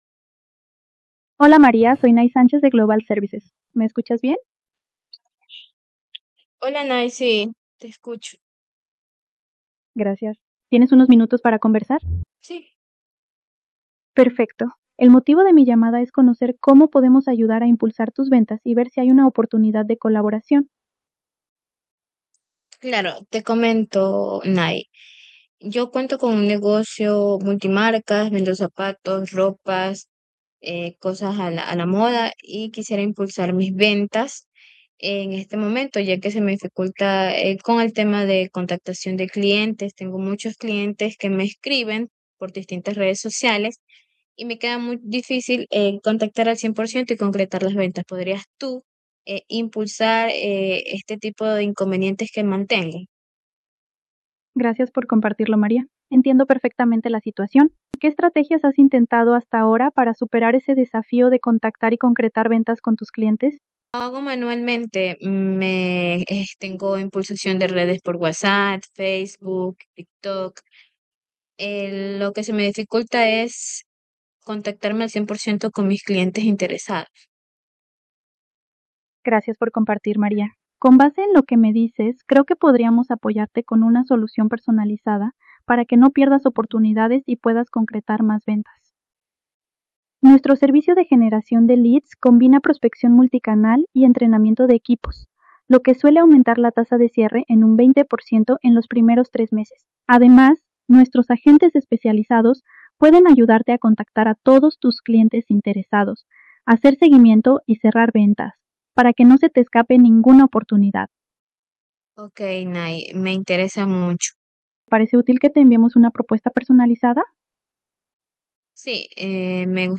Llamada de Global con IA
Escucha cómo interactúa con un cliente real.
Realiza tu gestión con IA, donde agentes virtuales llaman, negocian y cobran con voz natural y empatía.
llamada.mp3